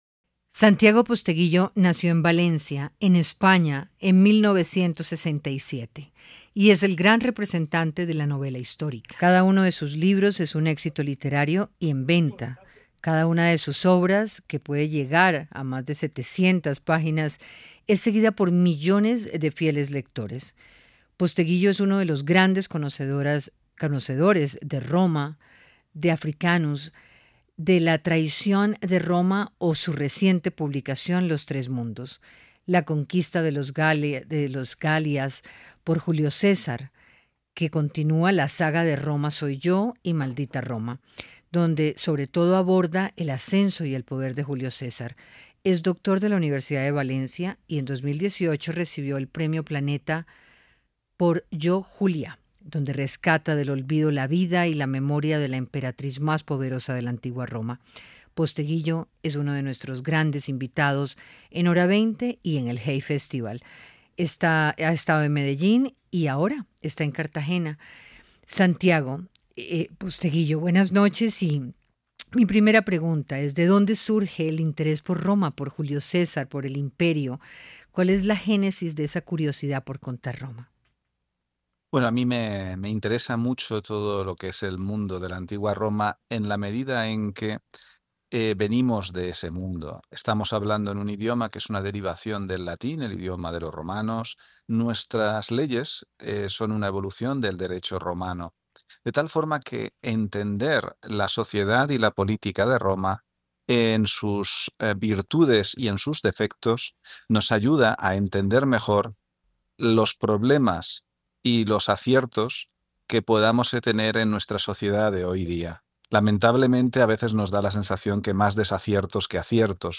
En el marco del Hay Festival, el escritor español habla de la necesidad de entender la antigua Roma para enfrentar el mundo político que nos rodea